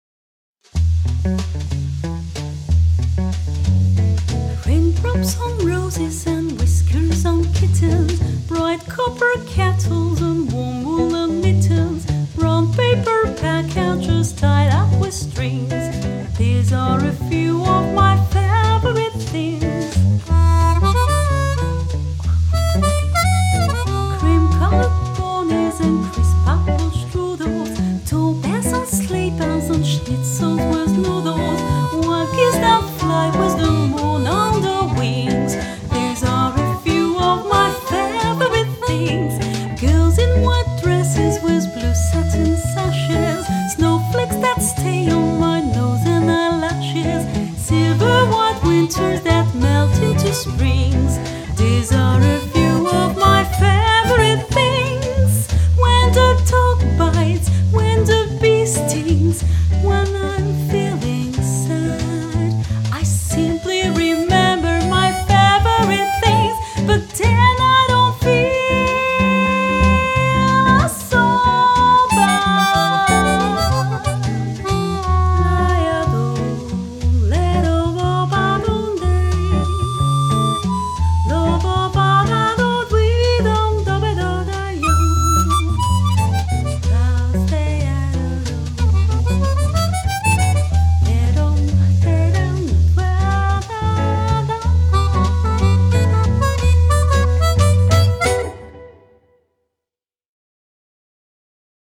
- Soprano